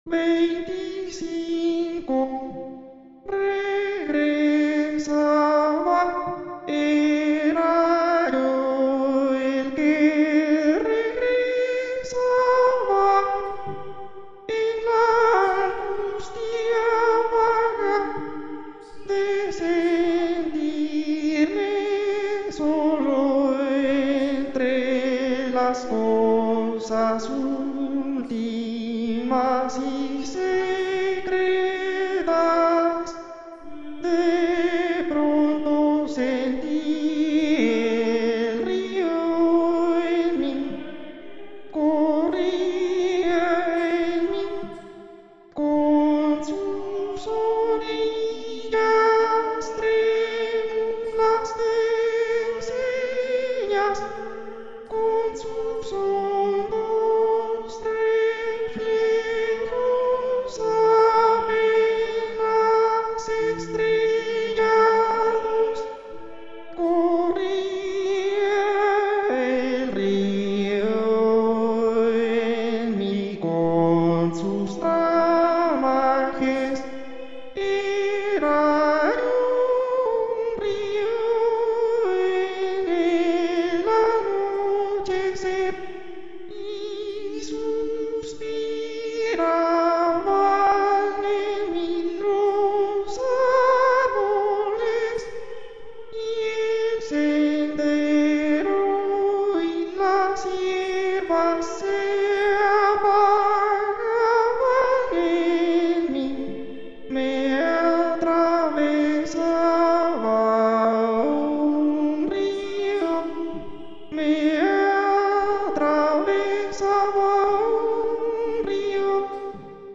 sound installation
Each message sent from the web site is sang in the restrooms by an electronic agent.
The algorithm plays back the message at the selected bathroom; the electronic sound is digitally reverberated to transform the acoustics of the site in order to create the illusion of voices singing in a small chapel.
Sound recording done at men bathroom
male.mp3